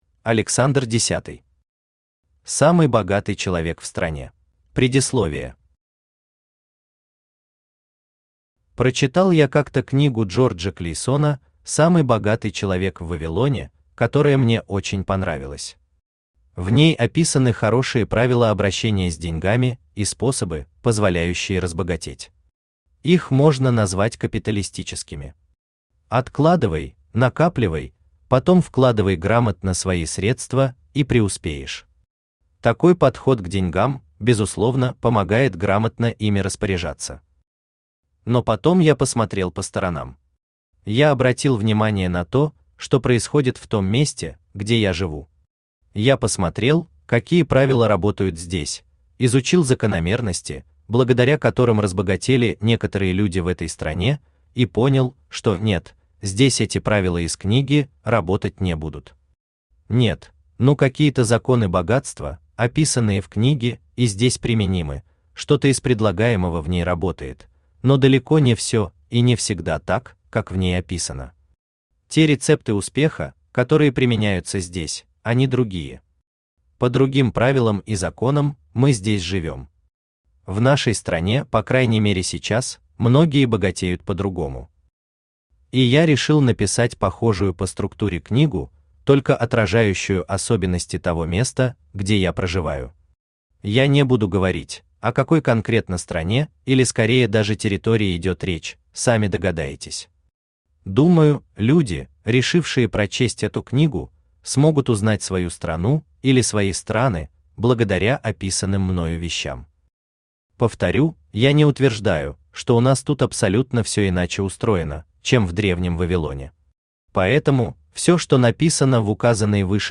Читает: Авточтец ЛитРес
Аудиокнига «Самый богатый человек в стране».